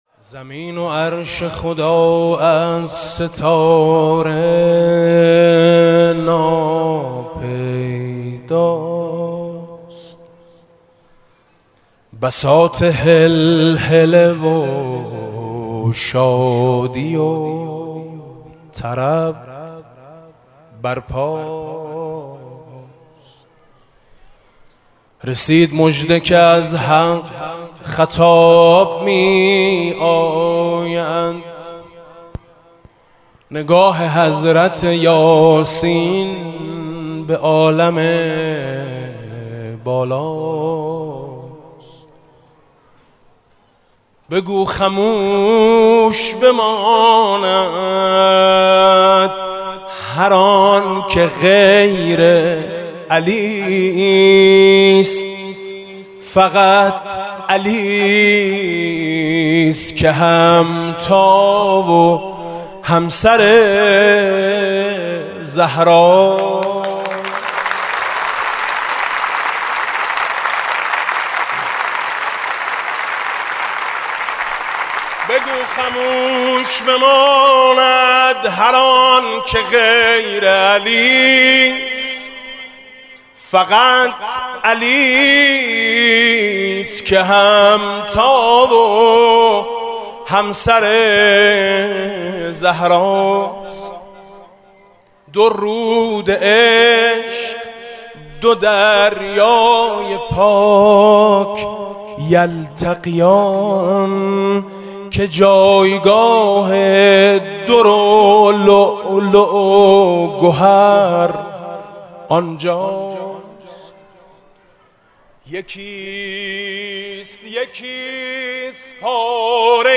در این فایل صوتی، گلچین مولودی سالروز ازدواج حضرت زهرا (س) و امام علی (ع) با صدای حاج میثم مطیعی را می‌شنوید.
کنار فاطمه حیدر زره نمی‌خواهد (مدح)
که شب شبِ دامادی حیدره (سرود فارسی و عربی)